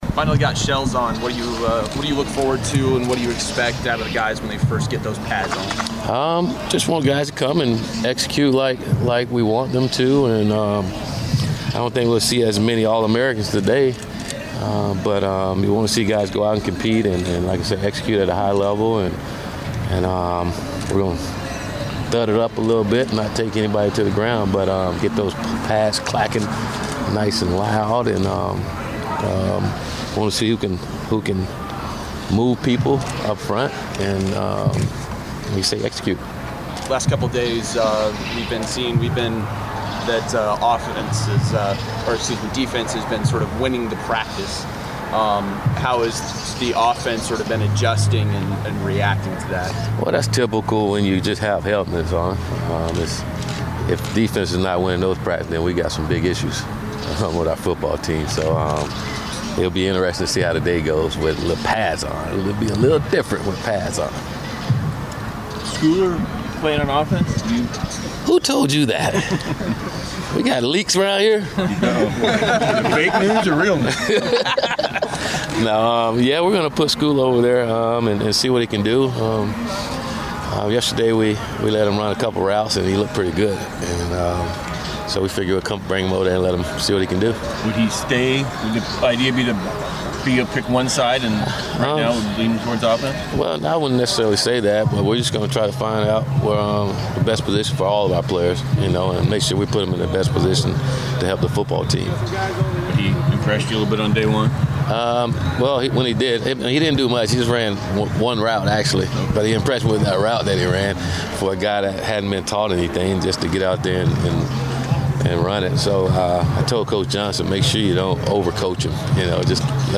Willie Taggart Media Session 8-3-17